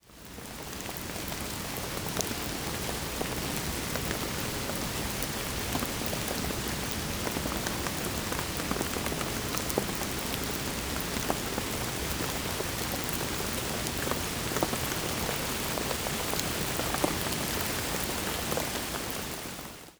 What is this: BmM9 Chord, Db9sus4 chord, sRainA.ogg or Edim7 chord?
sRainA.ogg